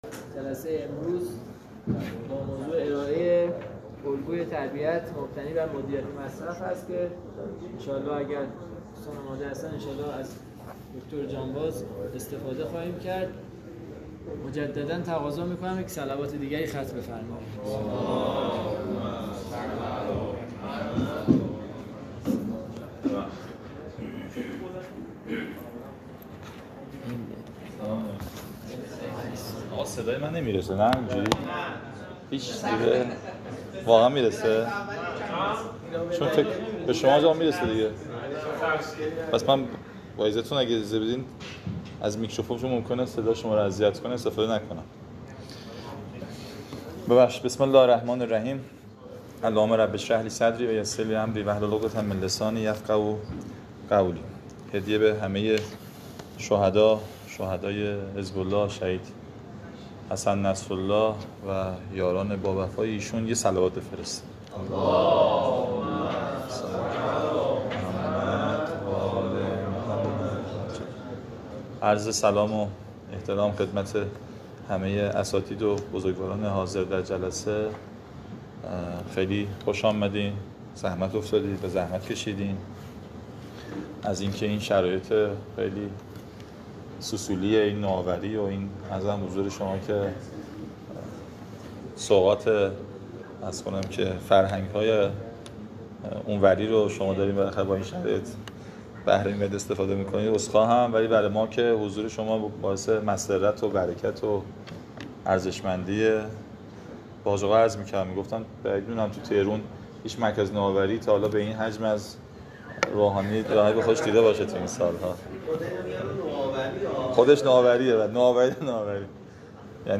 نشست علمی نهم موضوع: الگوی تربیت مبتنی بر مدیریت مصرف براساس گزارش وضعیت شناسی محصولات تربیتی تولید شده در عرصه کتاب و گیم و ...
محل برگزاری: مرکز امید حوزه هنری